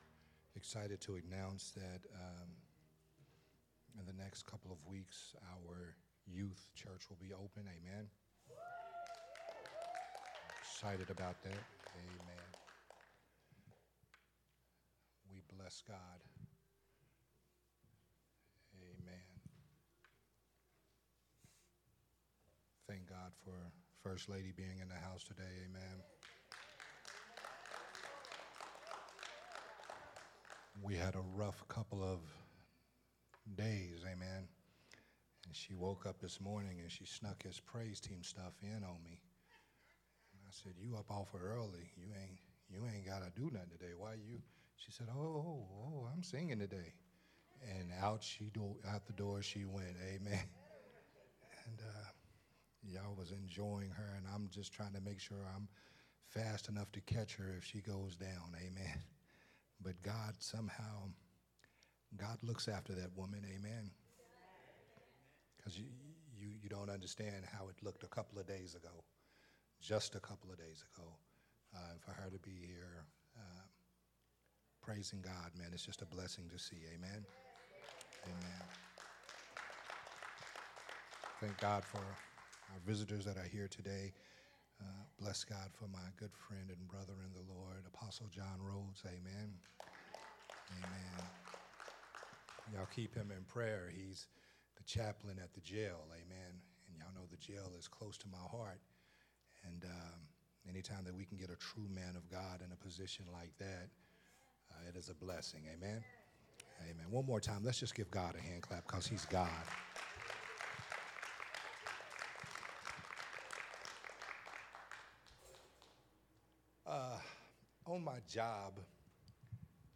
sermon series
recorded at Unity Worship Center on October 6th, 2024.